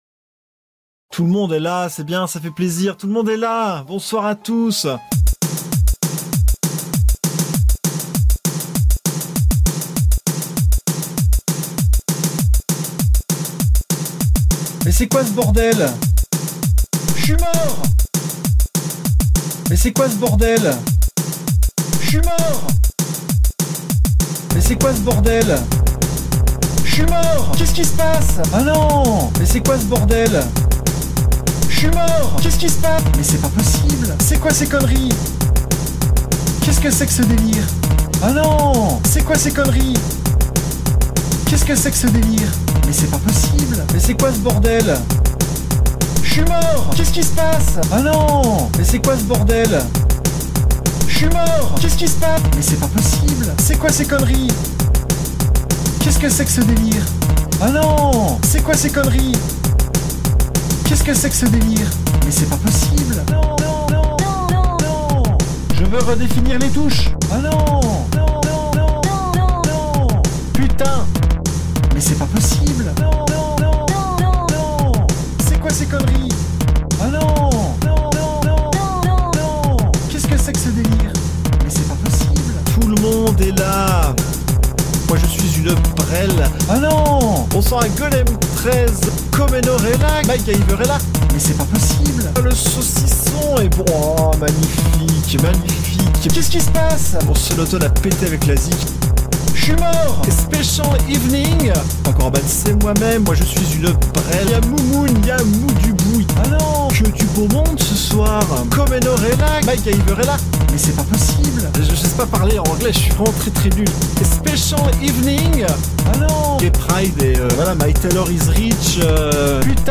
Extrait du spectacle Jamel 100% Debouze, Amstrad-CPC 464 megagiga 12 bits.